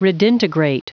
Prononciation du mot redintegrate en anglais (fichier audio)
Prononciation du mot : redintegrate